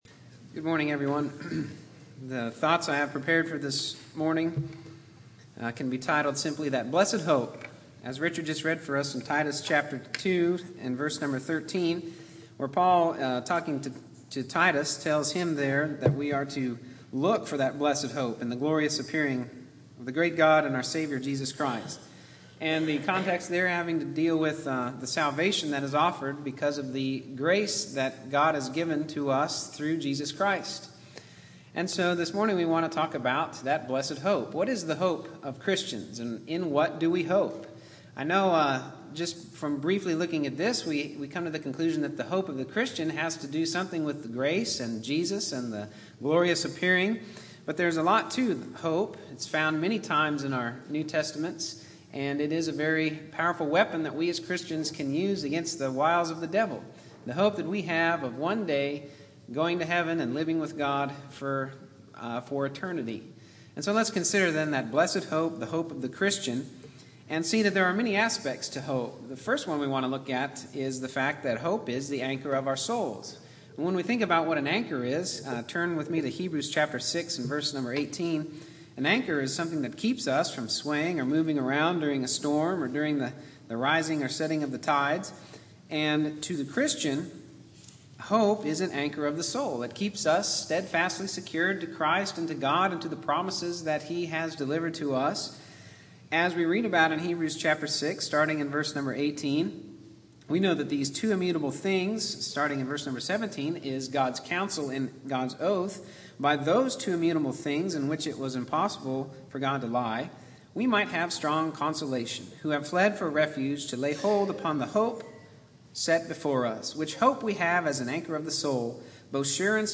Click here to download .mp3 Categories: Sermons Tags